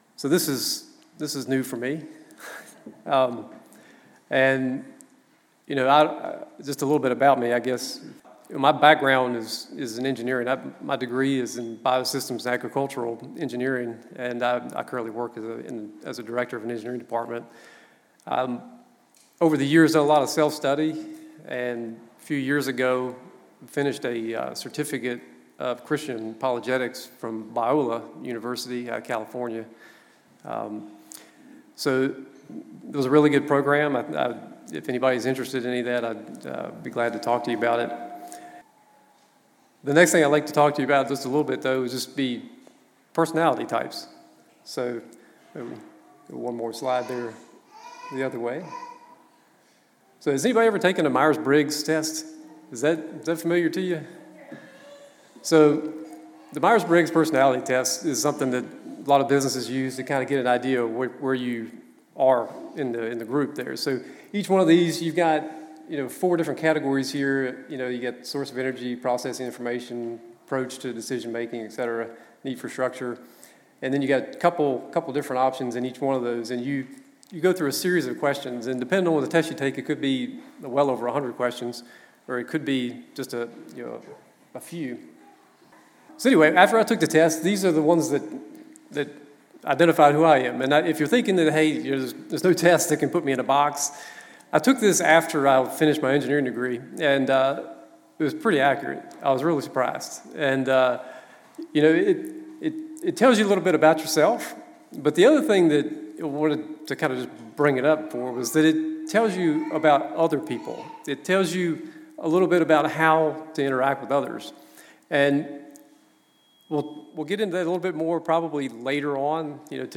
Note: Minor technical issue – some small skips in audio in a few places.